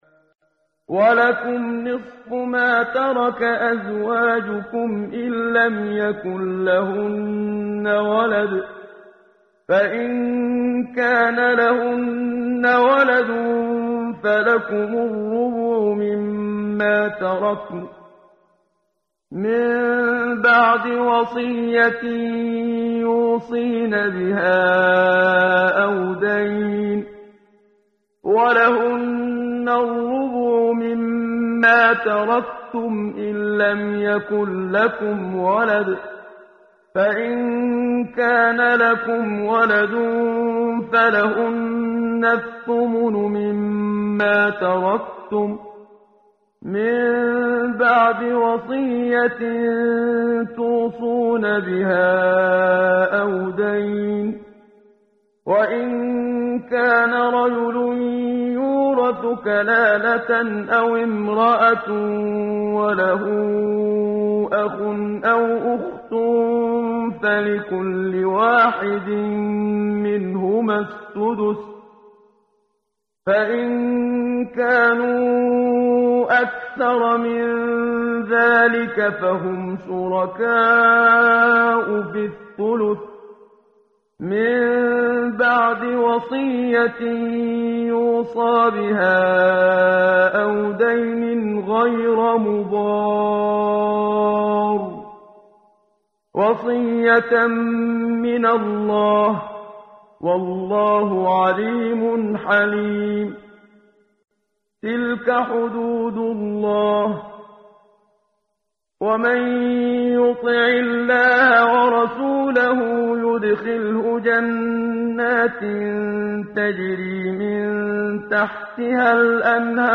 ترتیل صفحه 79 سوره مبارکه سوره نساء (جزء چهارم) از سری مجموعه صفحه ای از نور با صدای استاد محمد صدیق منشاوی
quran-menshavi-p079.mp3